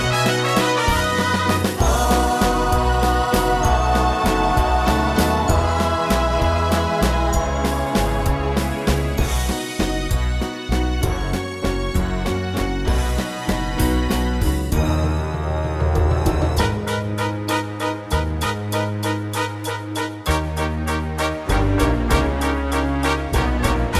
One Semitone Down Pop (1960s) 3:16 Buy £1.50